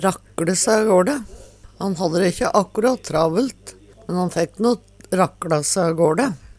DIALEKTORD PÅ NORMERT NORSK rakkLe se agåLe koma seg omsider avstad Eksempel på bruk Han har dæ ikkje akkurat travelt, men fækk no rakkLa se agåLe.